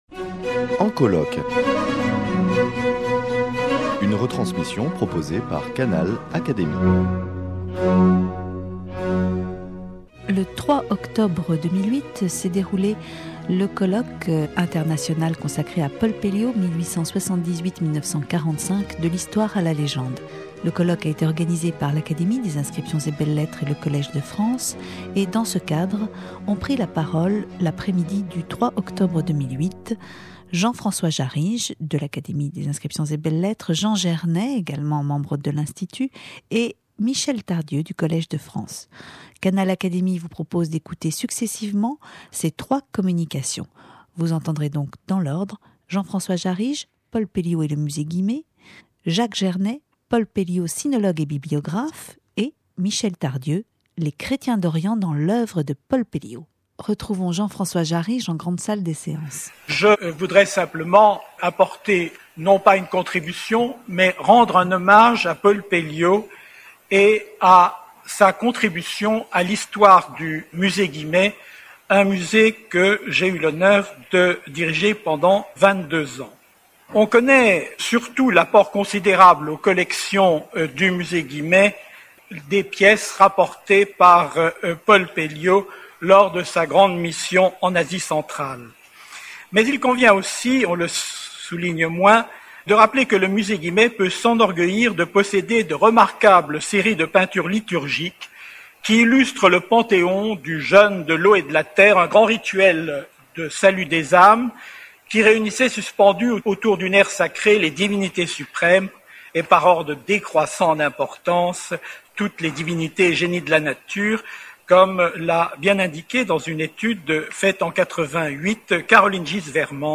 En octobre 2008, le Collège de France et l’Académie des inscriptions et belles lettres (AIBL) ont organisé un colloque international autour de la figure du grand sinologue Paul Pelliot (1878-1945).